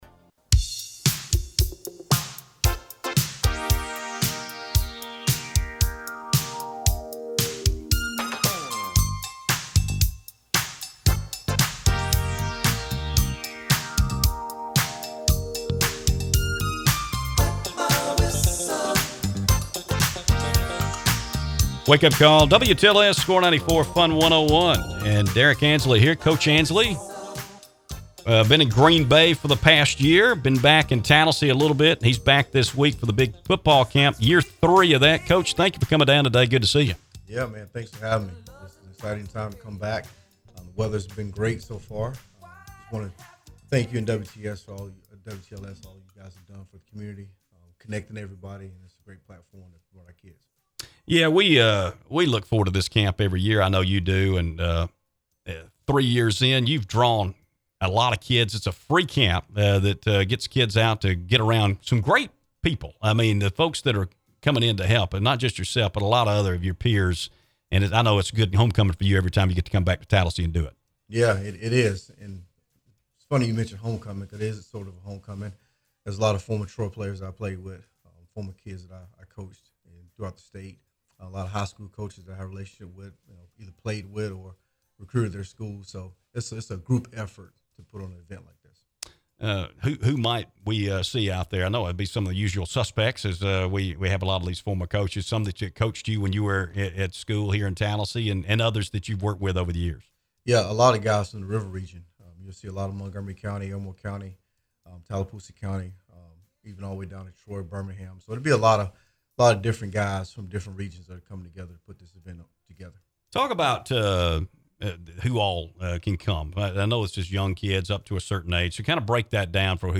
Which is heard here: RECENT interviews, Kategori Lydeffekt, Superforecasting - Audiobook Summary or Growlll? RECENT interviews